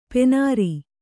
♪ penāri